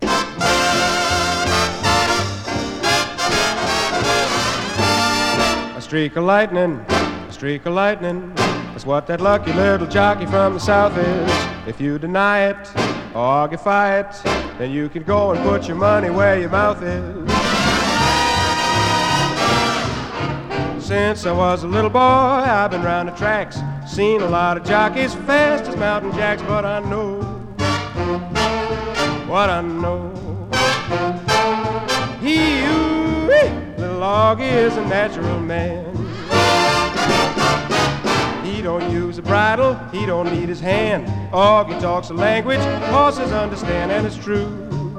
Jazz, Pop, Vocal, Big Band　USA　12inchレコード　33rpm　Stereo